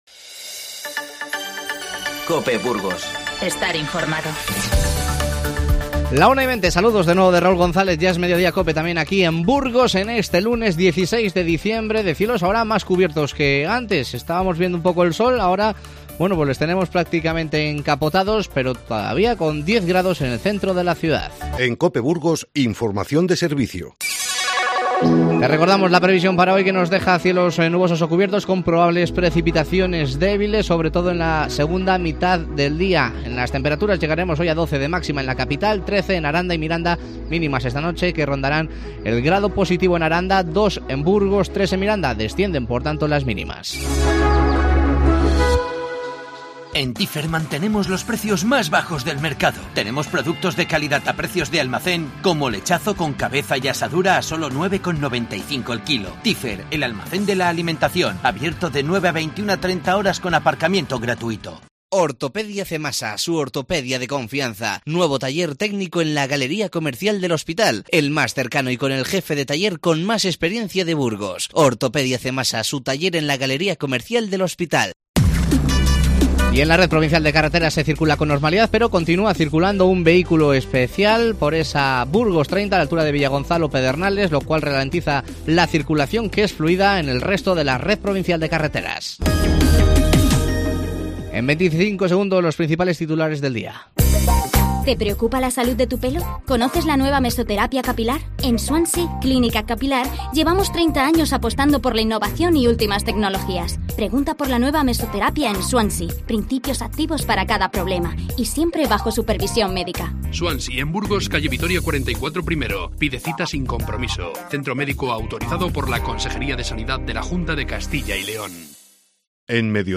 Escucha los principales titulares del día y la entrevista